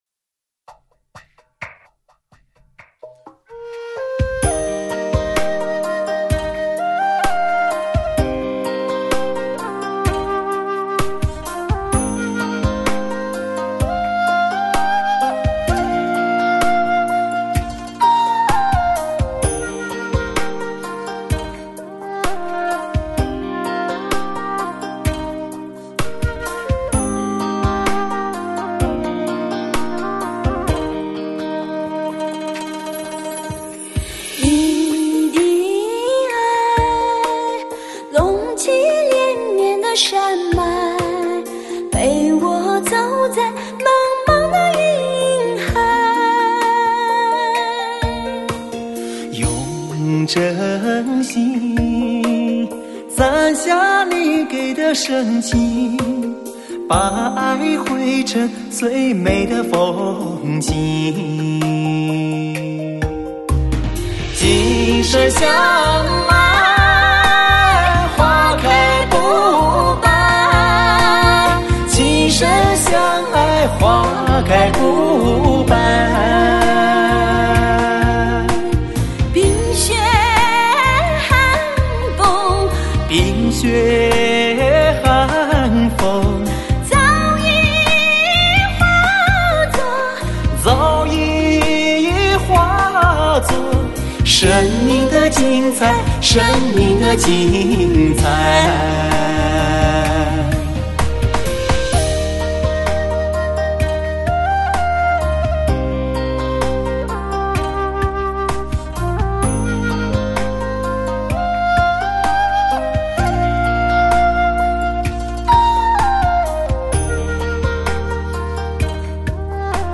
德国1:1直刻黑胶    新歌百分百    流行前线 时尚节拍……